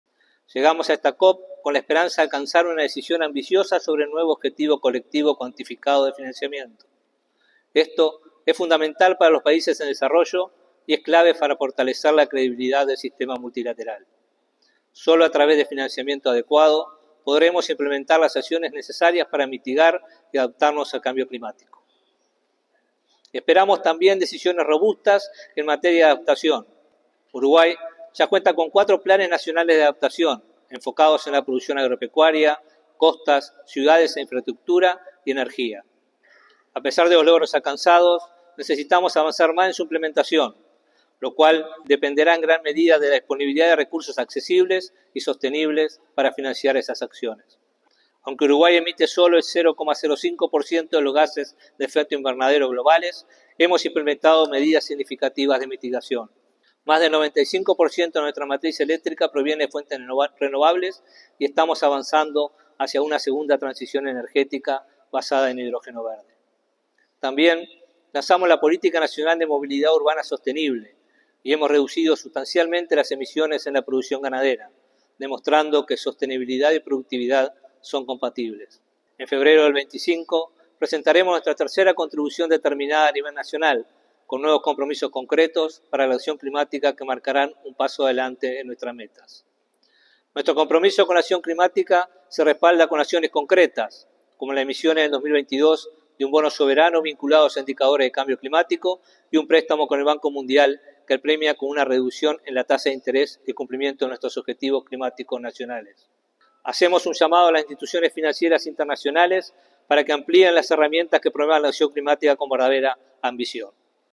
Palabras del ministro de Ambiente, Robert Bouvier
Palabras del ministro de Ambiente, Robert Bouvier 19/11/2024 Compartir Facebook X Copiar enlace WhatsApp LinkedIn En el marco de su participación en la Conferencia sobre el Cambio Climático organizada por Naciones Unidas (COP29), el ministro de Ambiente, Robert Bouvier, advirtió que el financiamiento climático es impostergable y urgente.